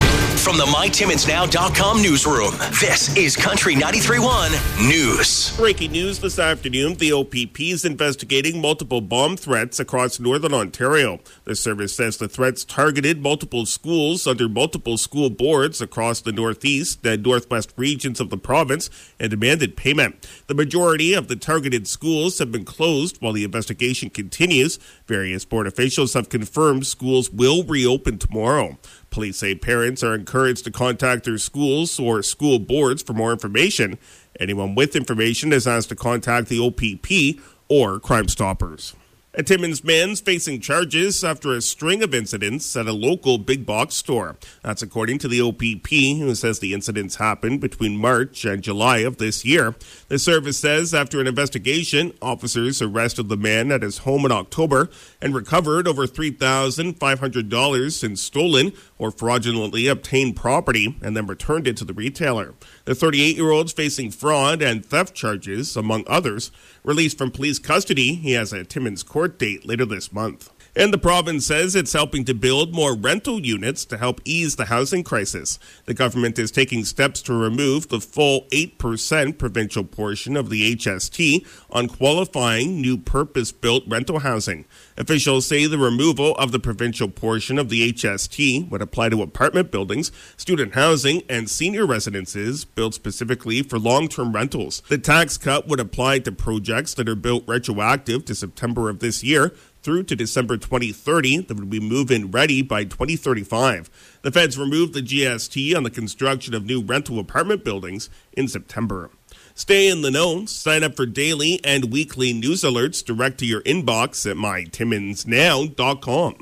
5:00pm Country 93.1 News – Wed., Nov. 01, 2023